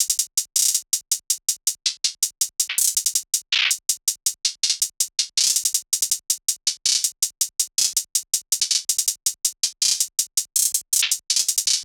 SOUTHSIDE_beat_loop_bread_hihat_02_162.wav